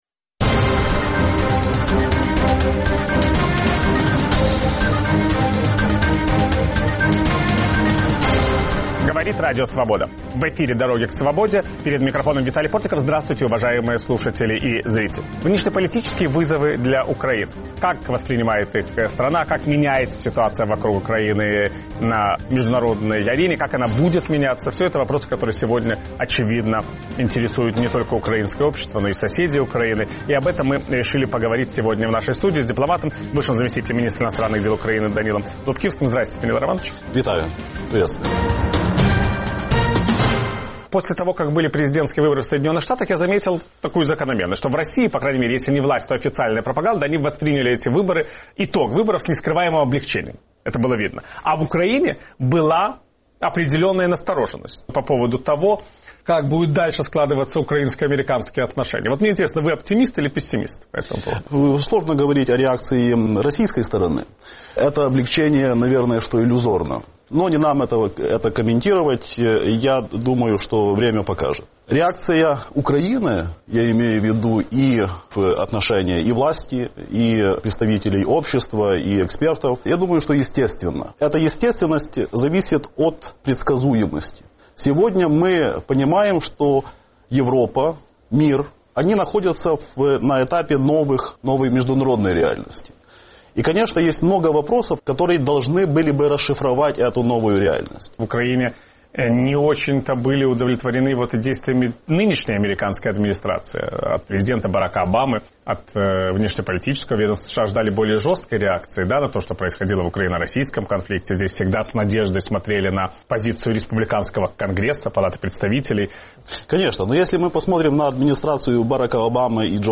Виталий Портников беседует с бывшим заместителем министра иностранных дел Украины Данилой Лубкивским.